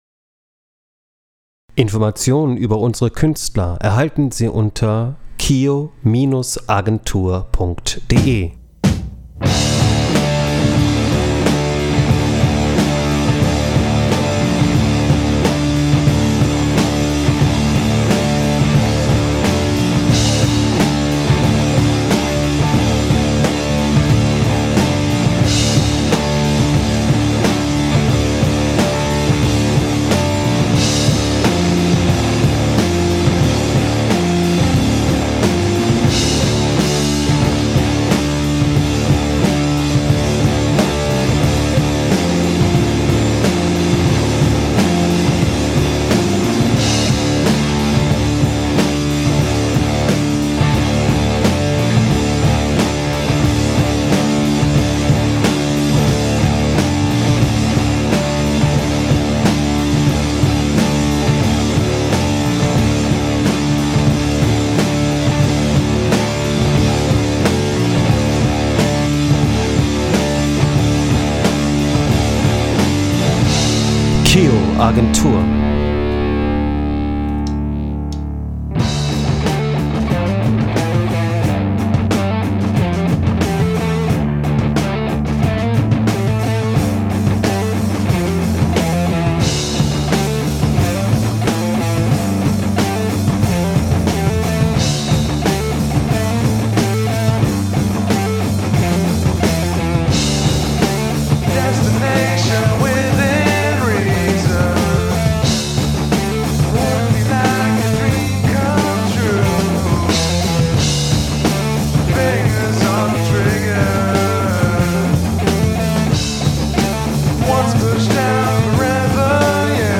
Gitarre
Bass/ Gesang
Schlagzeug